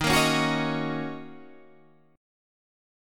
D#m7 chord